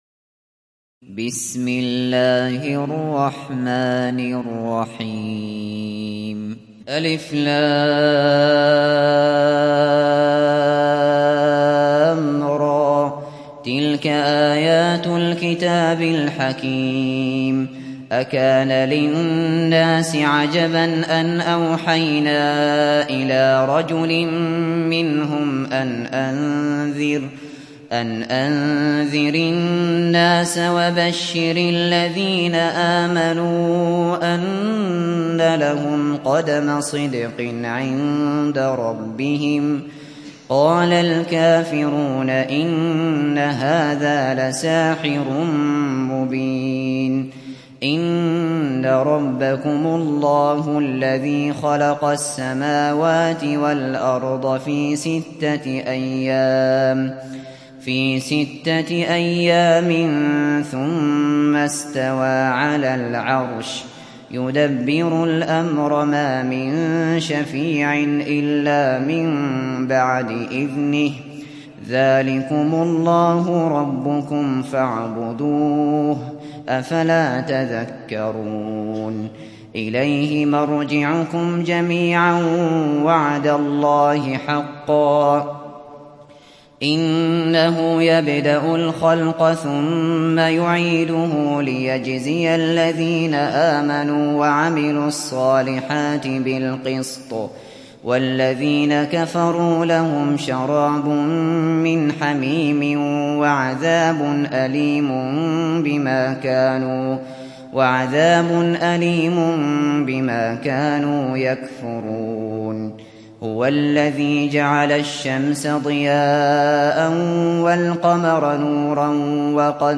دانلود سوره يونس mp3 أبو بكر الشاطري روایت حفص از عاصم, قرآن را دانلود کنید و گوش کن mp3 ، لینک مستقیم کامل